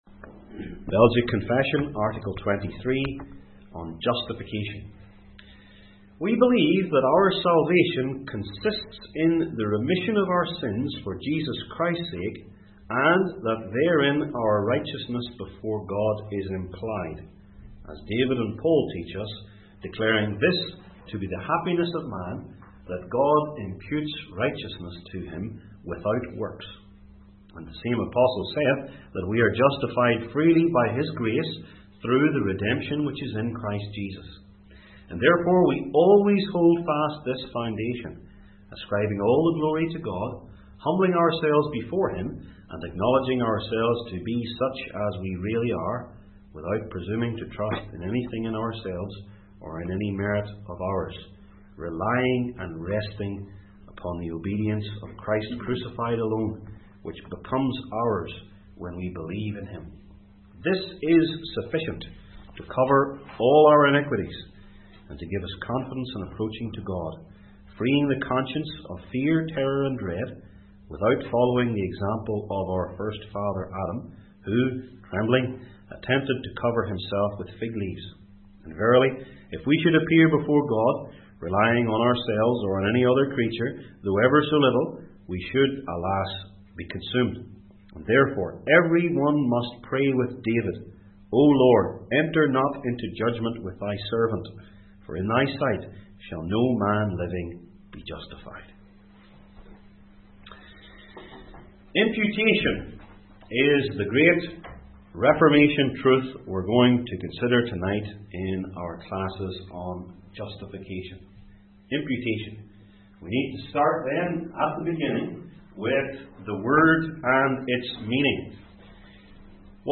22-25 Service Type: Belgic Confession Classes Article 23